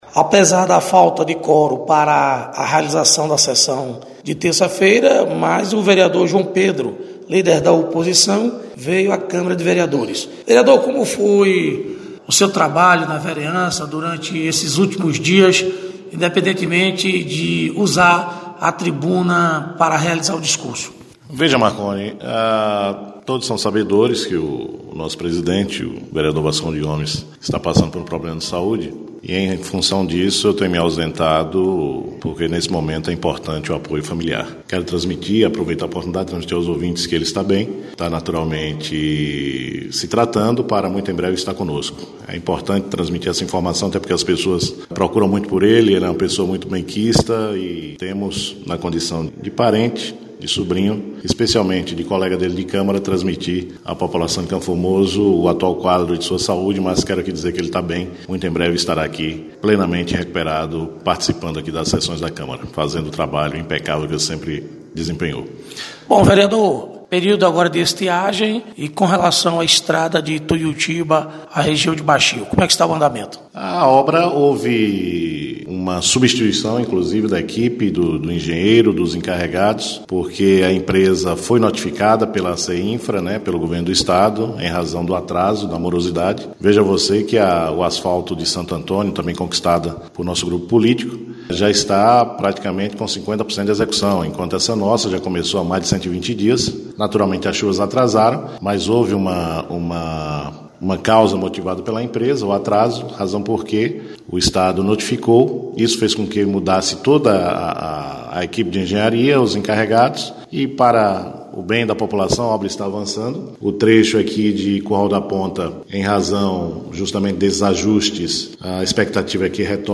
Entrevista: Vereadores do município